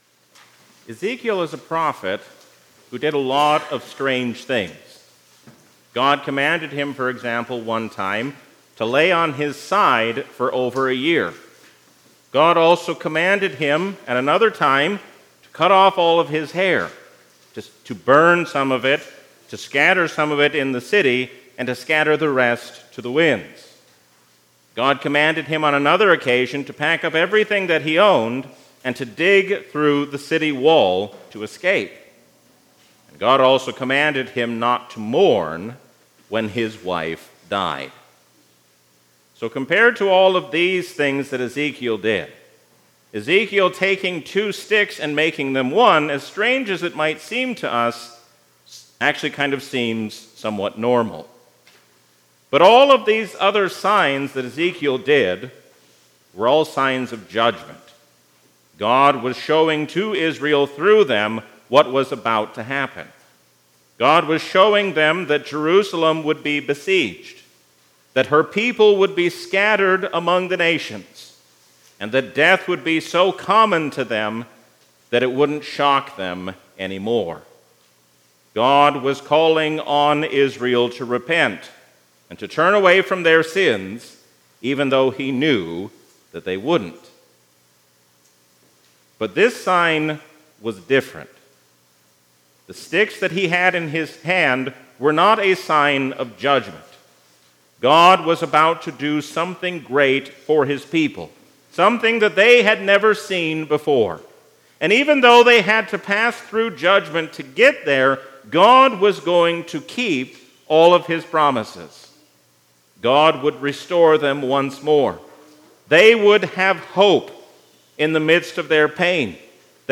A sermon from the season "Lent 2024." All those who know the truth listen to the voice of Jesus, because He is the Truth who has come to set us free. The reading is John 18:28-40.